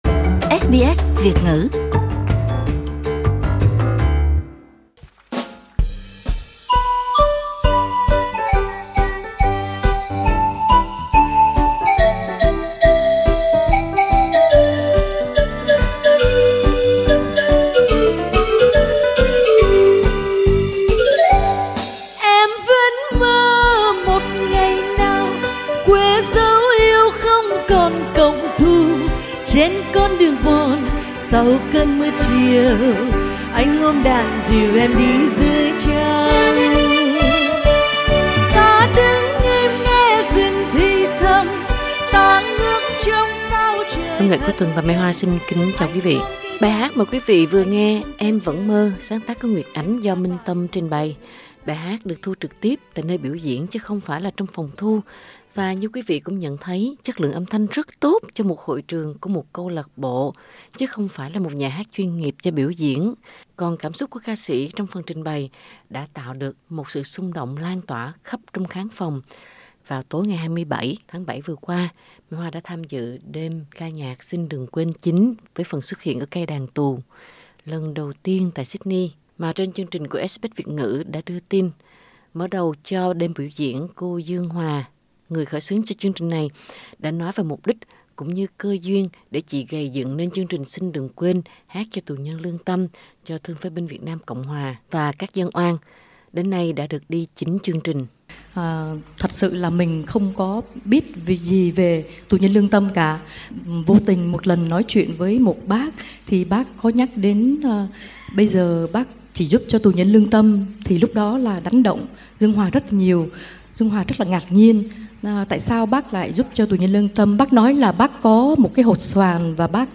Chương trình Xin Đừng Quên 9 với sự xuất hiện lần đầu tiên của Cây Đàn Tù tại Sydney đã diễn ra vào tối 27/7 vừa qua tại Smithfiled RSL Club, Sydney.